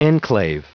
Prononciation du mot enclave en anglais (fichier audio)
Prononciation du mot : enclave